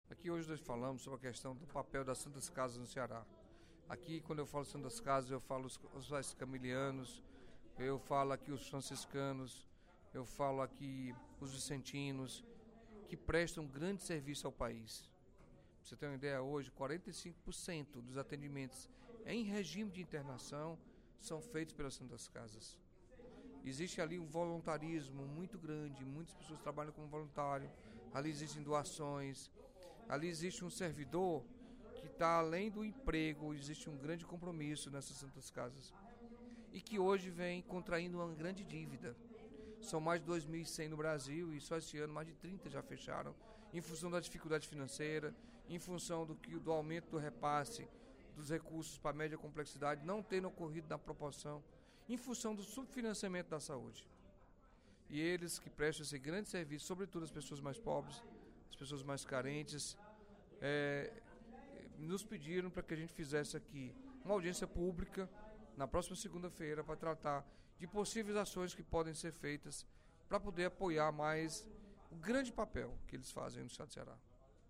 O deputado Carlos Felipe (PCdoB) ressaltou, no primeiro expediente da sessão plenária desta quarta-feira (03/06), o trabalho dos hospitais filantrópicos do Ceará, citando, como exemplo, a Santa Casa de Misericórdia do Ceará.